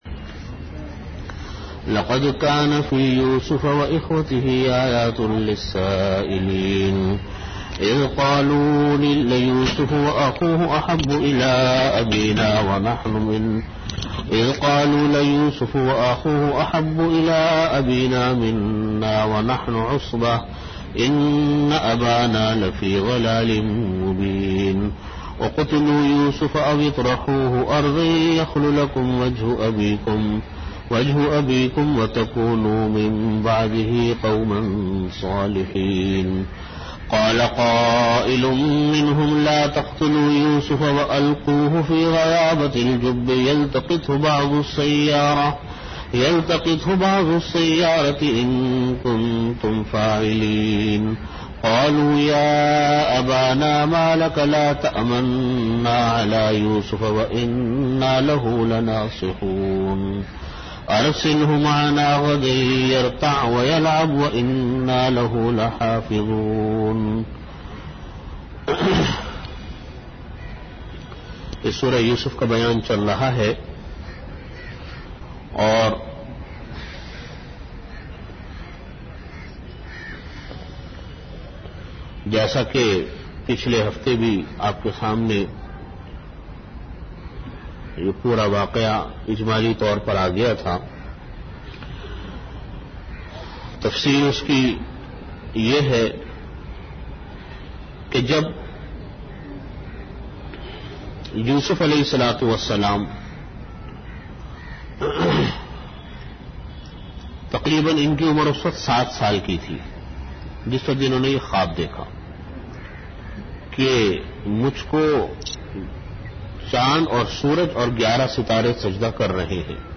Audio Category: Bayanat
Time: After Asar Prayer Venue: Jamia Masjid Bait-ul-Mukkaram, Karachi